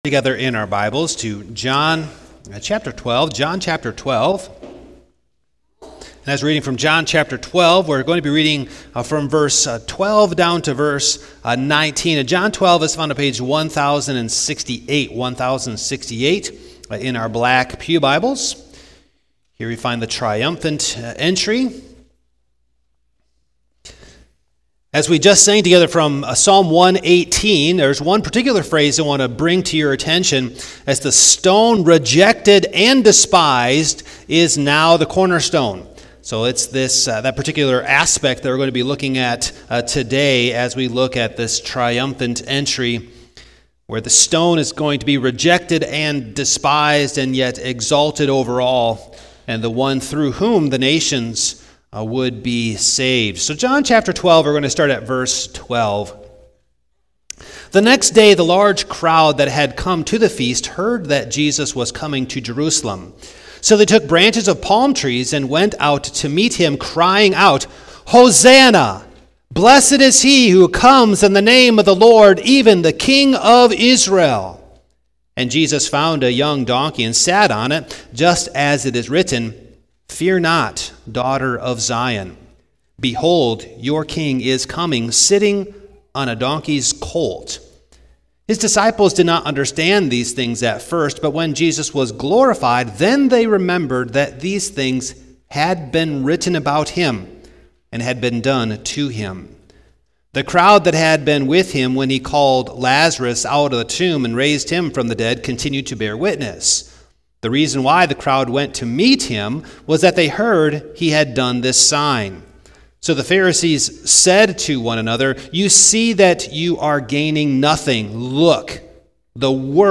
2025 The King is Coming Home Preacher
Passage: John 12:12-19 Service Type: Morning Download Files Notes « The Key of Church Discipline Remember